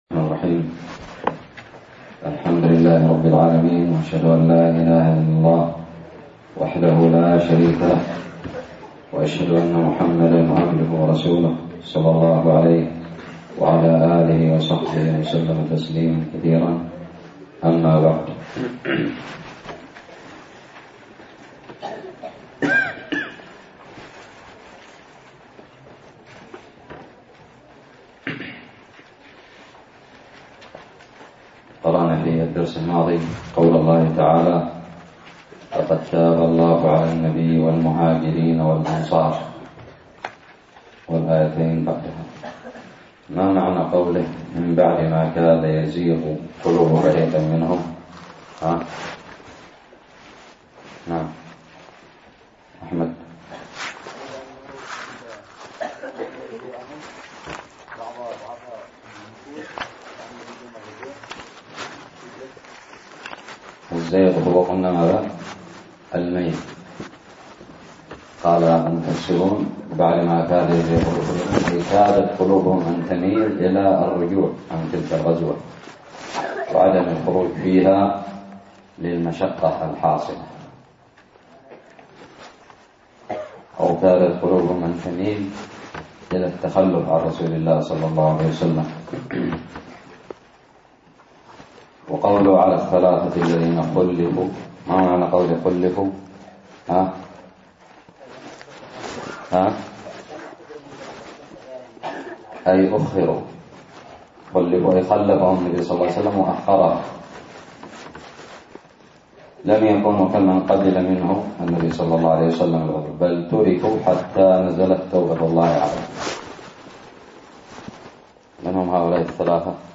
الدرس الثاني والخمسون من تفسير سورة التوبة
ألقيت بدار الحديث السلفية للعلوم الشرعية بالضالع